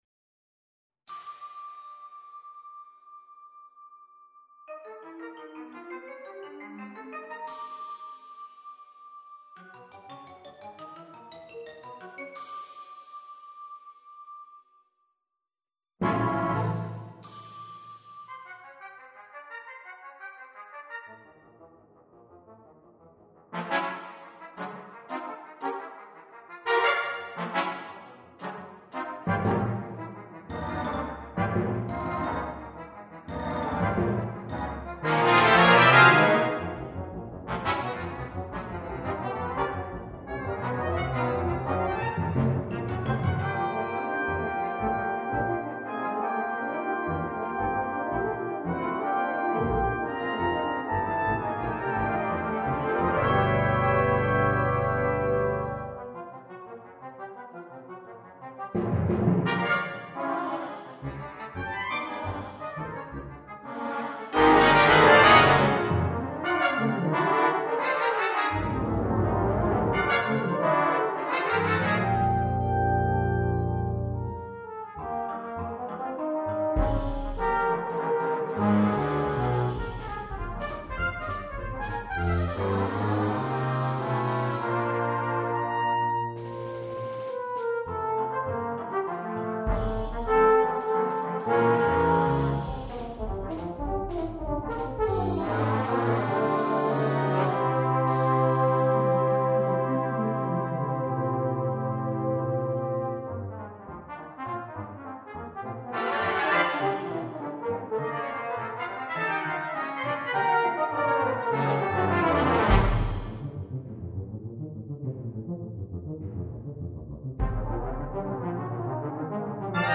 Sheet Music for Brass Band